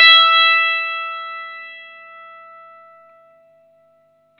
R12NOTE E +3.wav